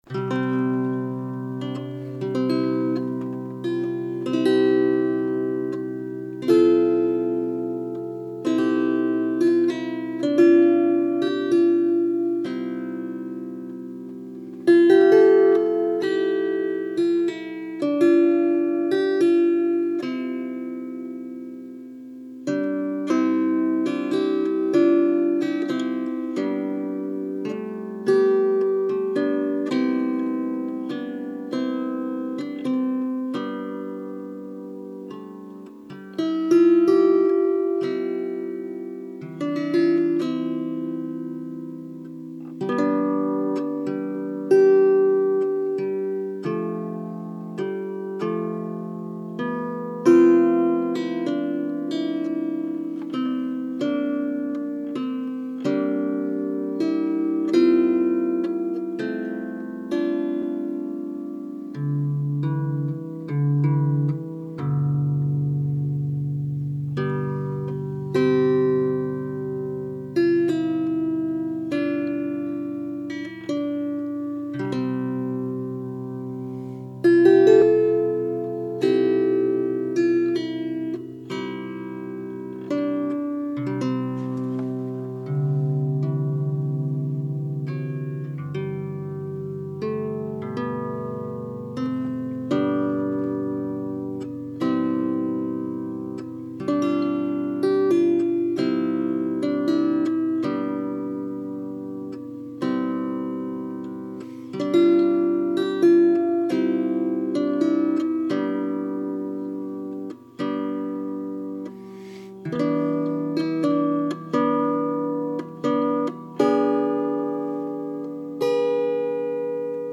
ライアーの響きが持つ” ゆらぎ” が究極のリラクゼーションをもたらしてくれるでしょう。